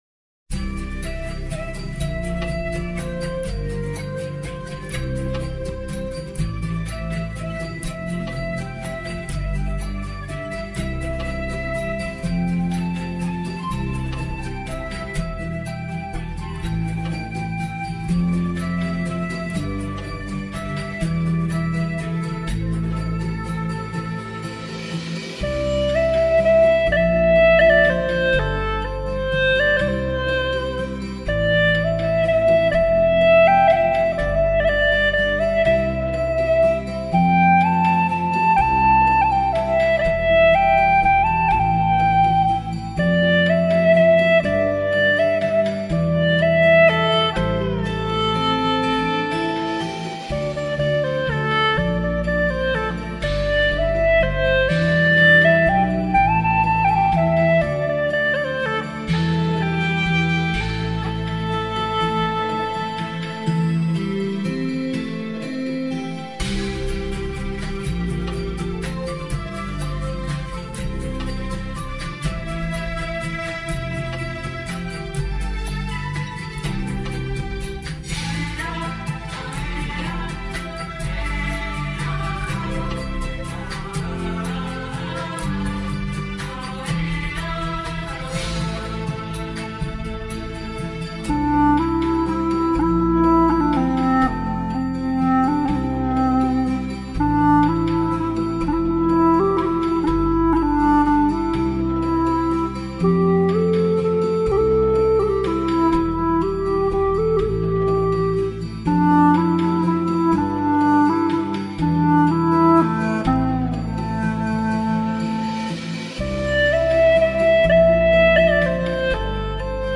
调式 : C 曲类 : 影视